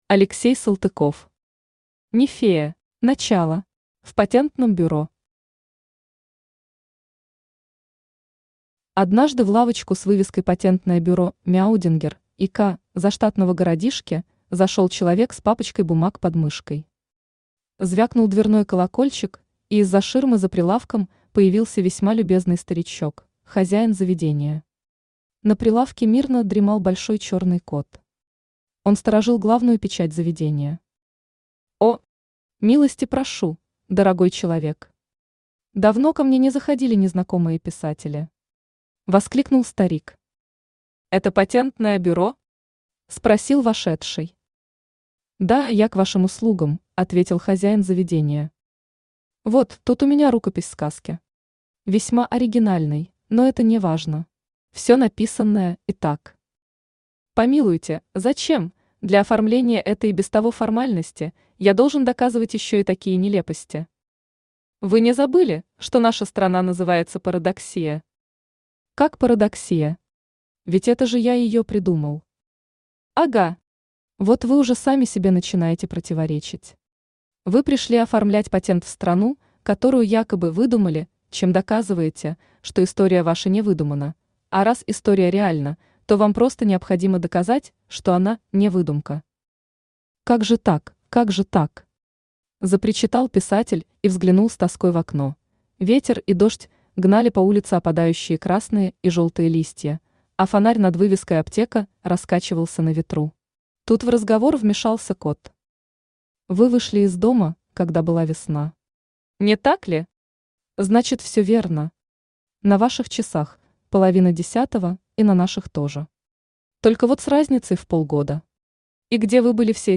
Аудиокнига Нефея | Библиотека аудиокниг
Aудиокнига Нефея Автор Алексей Васильевич Салтыков Читает аудиокнигу Авточтец ЛитРес.